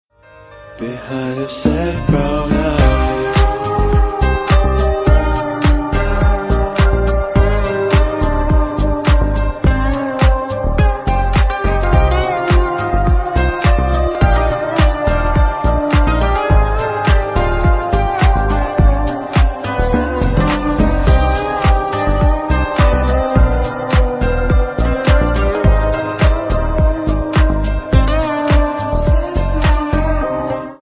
Клубные Рингтоны » # Спокойные И Тихие Рингтоны
Танцевальные Рингтоны